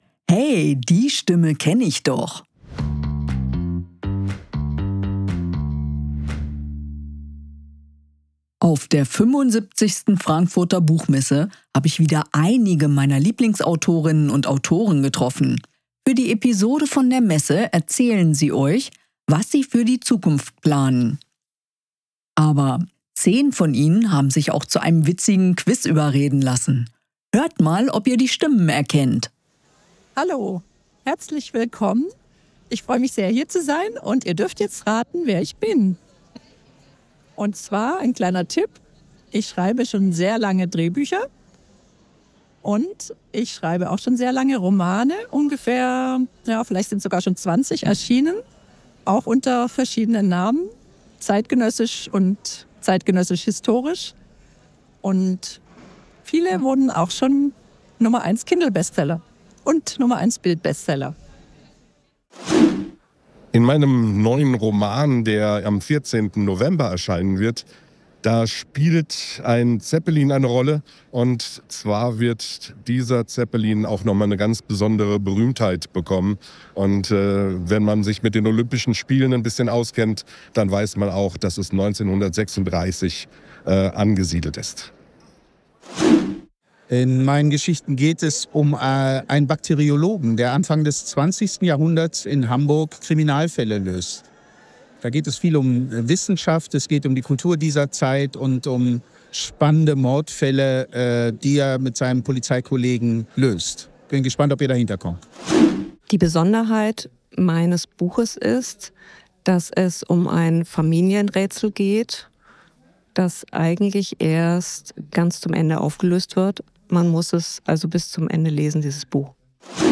Autoren auf der Frankfurter Buchmesse 2023